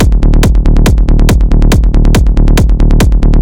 Synth und Kick basierend auf vsti...